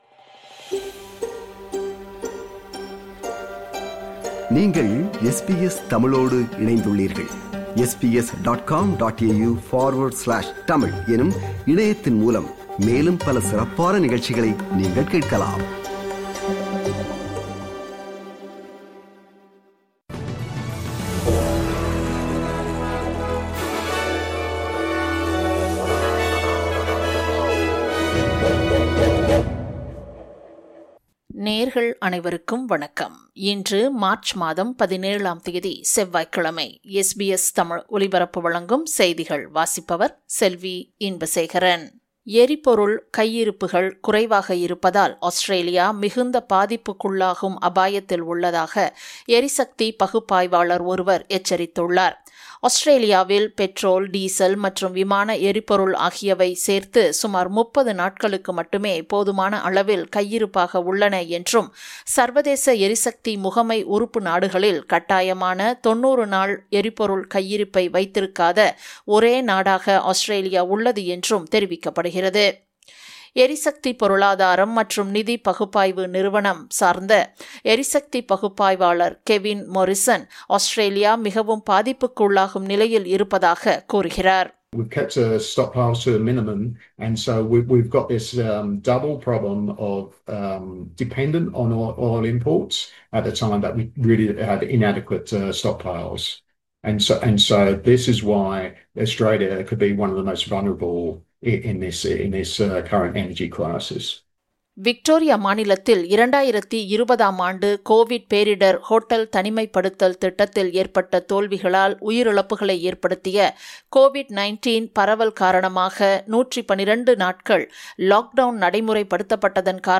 SBS தமிழ் ஒலிபரப்பின் இன்றைய (செவ்வாய்க்கிழமை 17/03/2026) செய்திகள்.